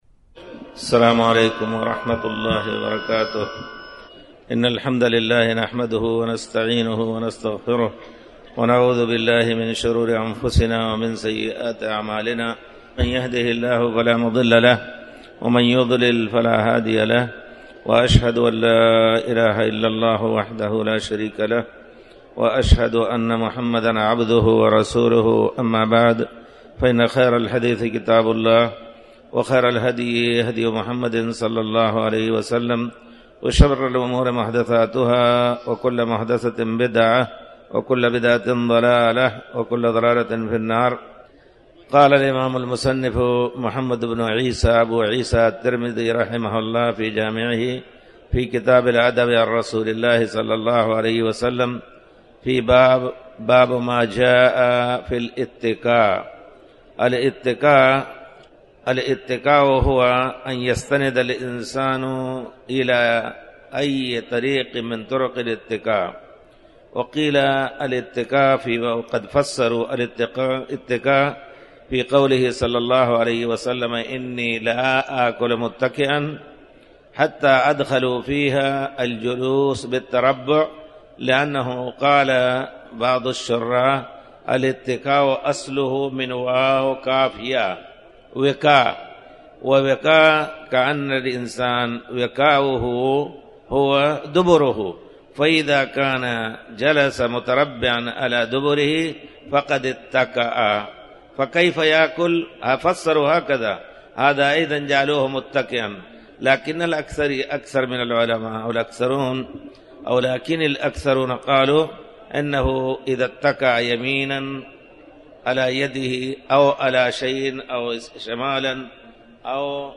تاريخ النشر ٣ رمضان ١٤٣٩ هـ المكان: المسجد الحرام الشيخ